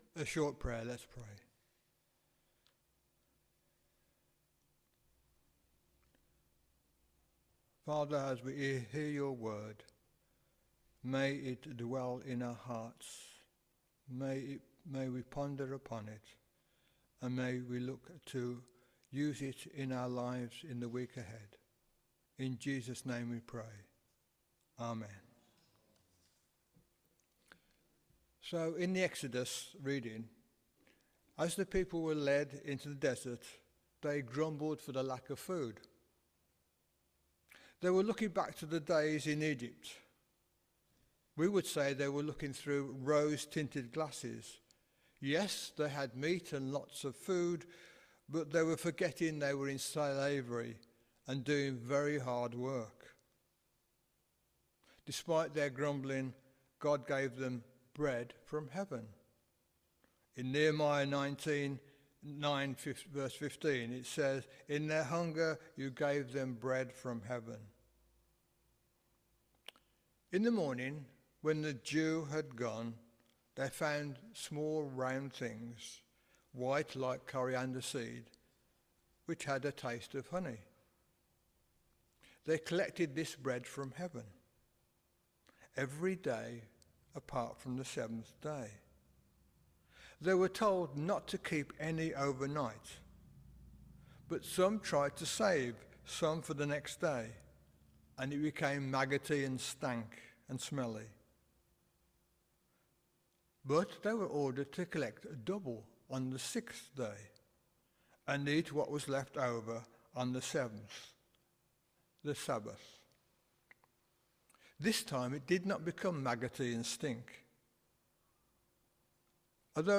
Media for Midweek Communion on Wed 23rd Jul 2025 10:00 Speaker
Theme: Bread from Heaven Sermon Search